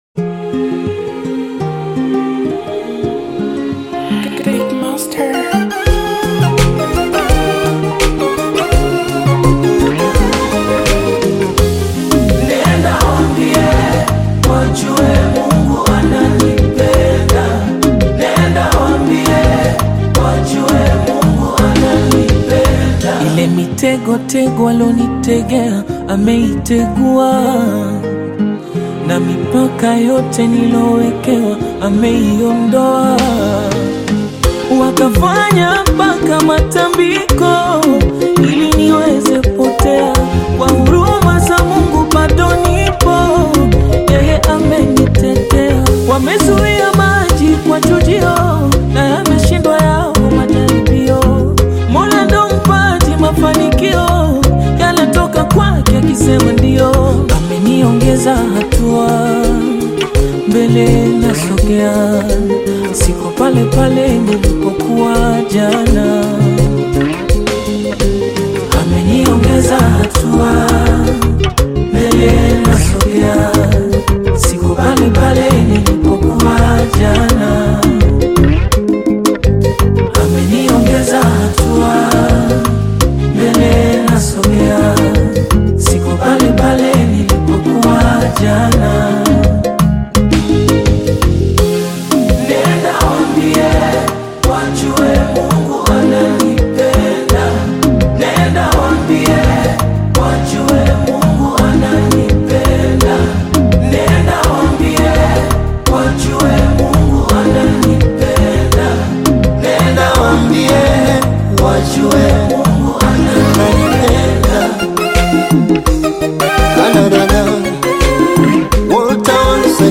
Gospel music track
Tanzanian gospel artists
Gospel song